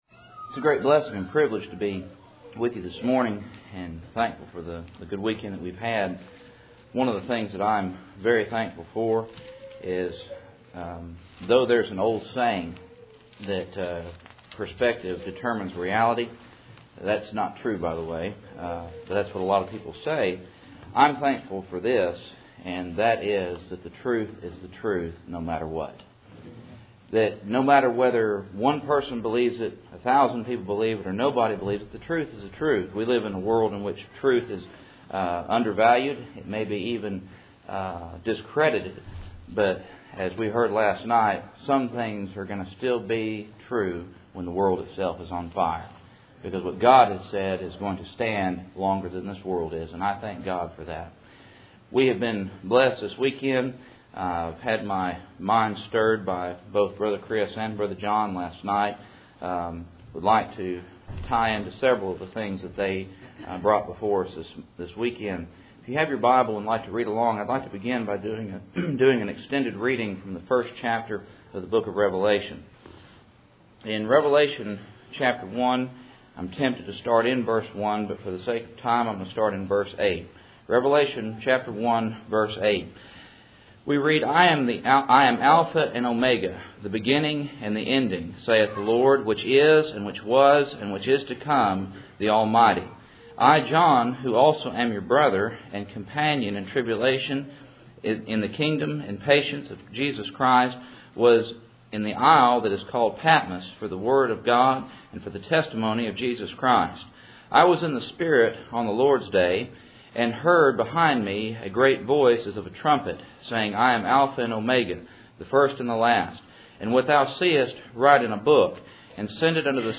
Cool Springs PBC March Annual Meeting